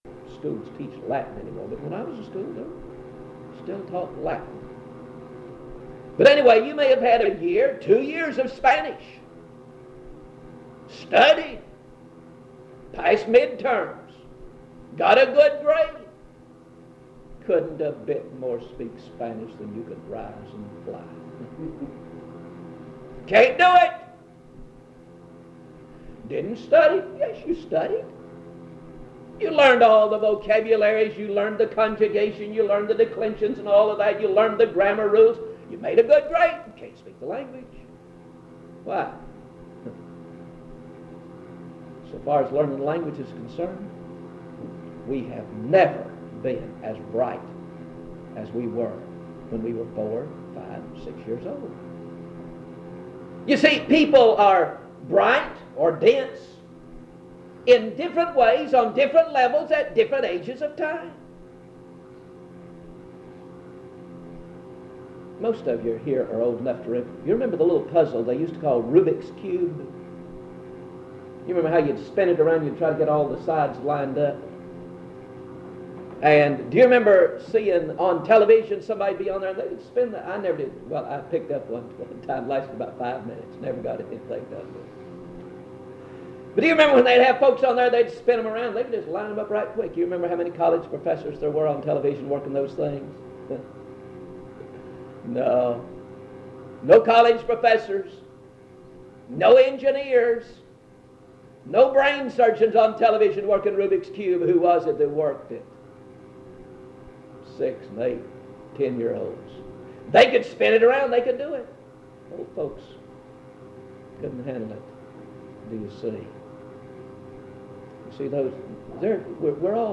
Browns Summit (N.C.)
In Collection: Primitive Baptist churches audio recordings Thumbnail Title Date Uploaded Visibility Actions PBHLA-ACC.004_002-B-01.wav 2026-02-12 Download PBHLA-ACC.004_002-A-01.wav 2026-02-12 Download